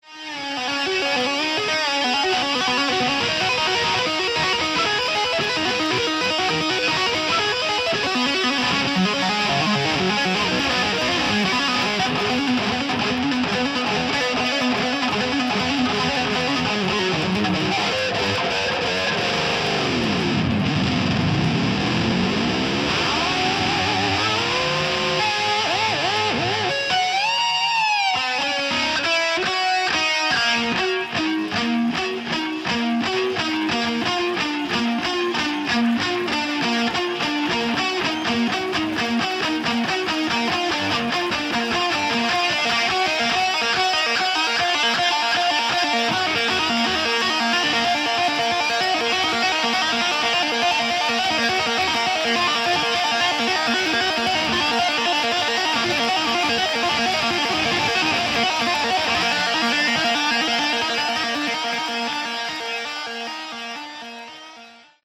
Category: Melodic Hard Rock
vocals, guitar
bass
drums